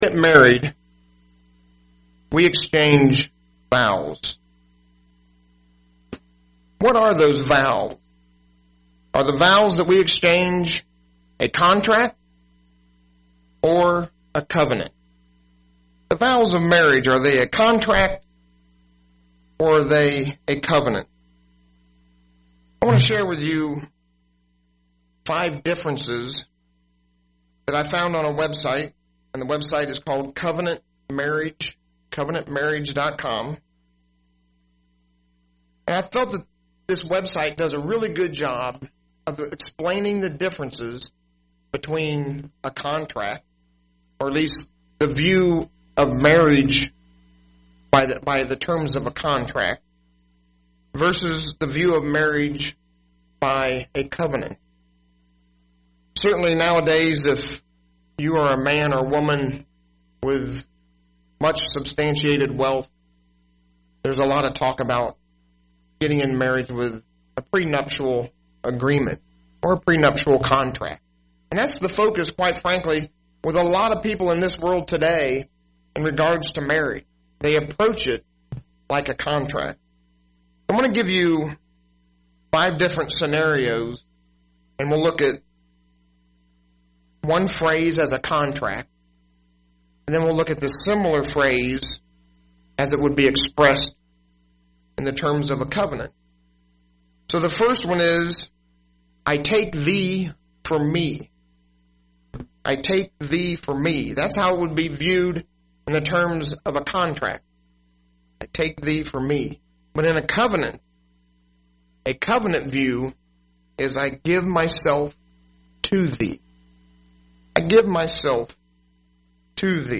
UCG Sermon Notes Notes: When we get married, is it a covenant or a contract?